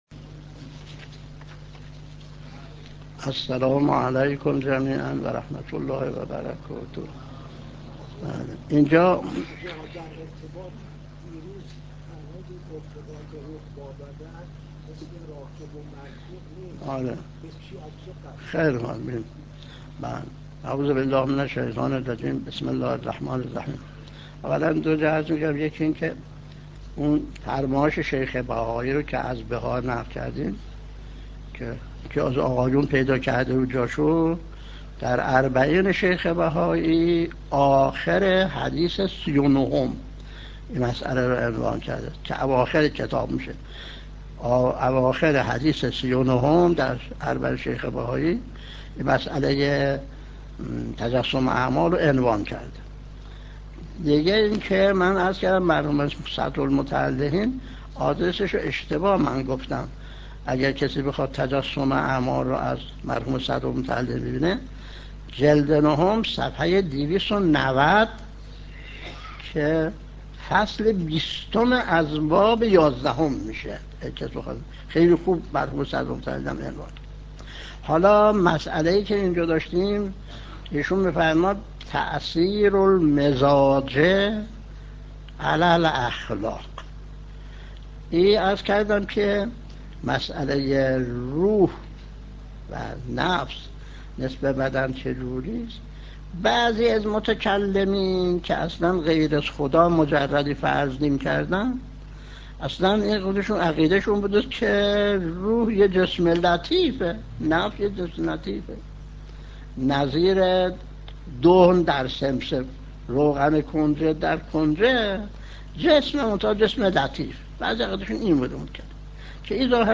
درس 24 : (1/8/1385)